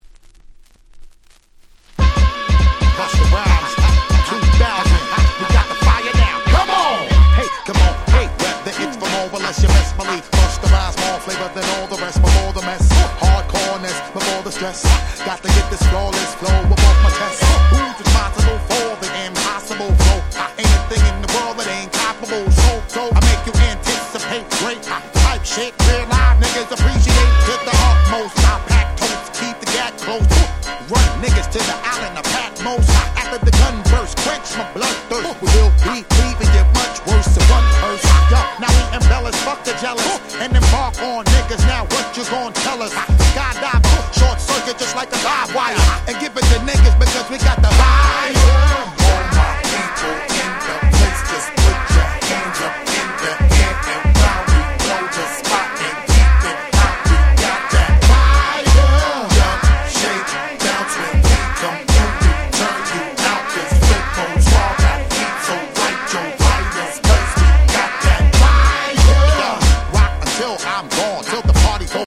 00' Smash Hit Hip Hop !!
タイトル通り熱くなれる格好良いフロアヒットです。